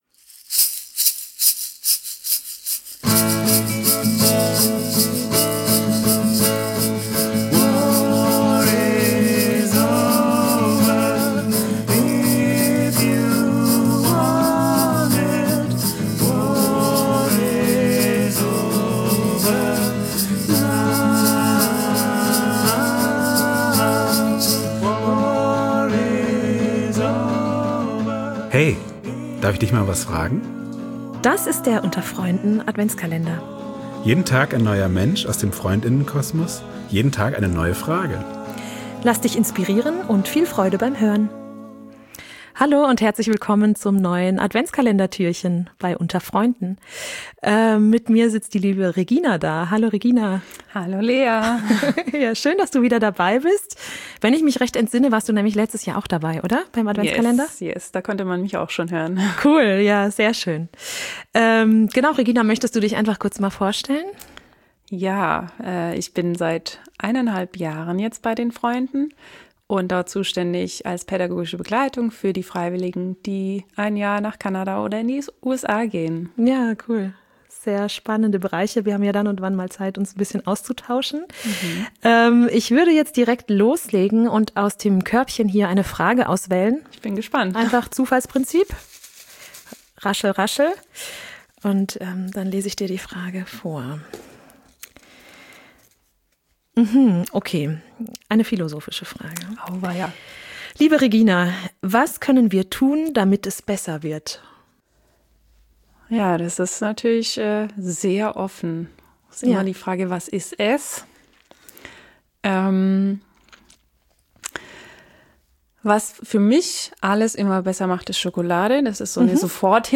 In unserem Adventskalender zum Hören wird jeden Tag eine andere Person aus dem Freund*innen-Kosmos zu einer persönlich-philosophischen Frage kurz interviewt und darf spontan dazu antworten.